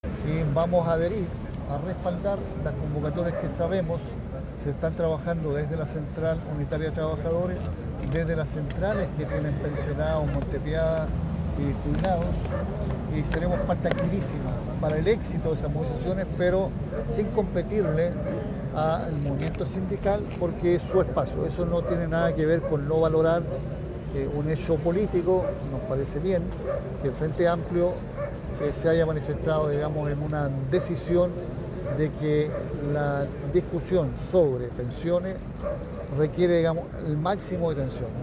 recabarren-entrevista-carmona-frente-amplio.mp3